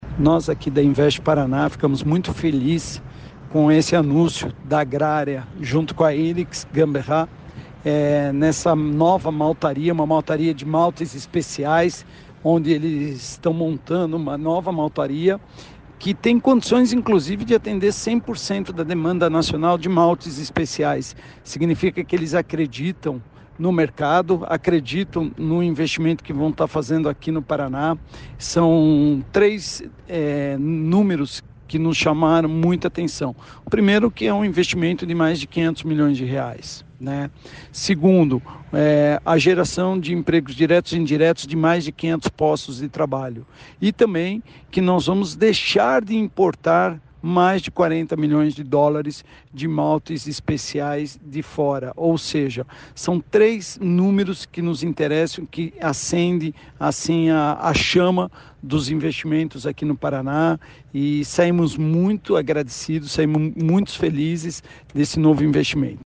Sonora do diretor-presidente da Invest Paraná, Eduardo Bekin, sobre o anúncio da instalação de uma maltaria em Guarapuava